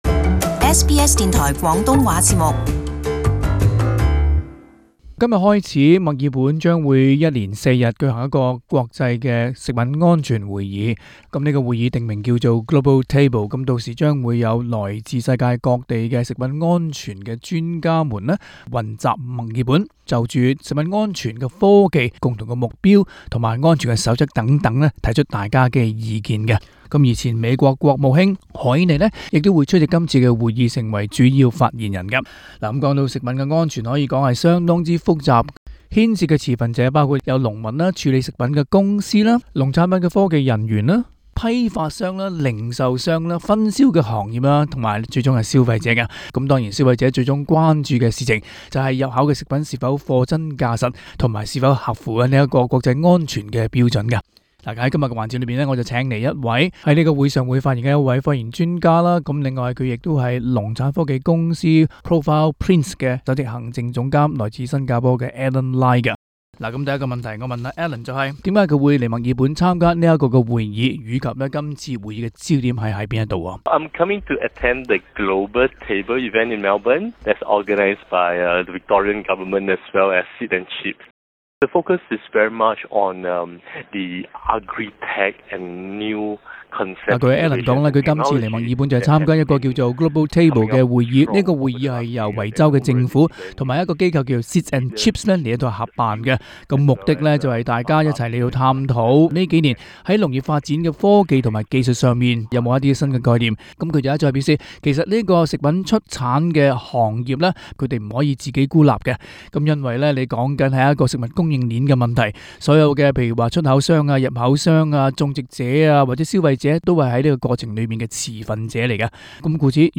【社區專訪】確保食物供應鏈安全無污染 絕對有可能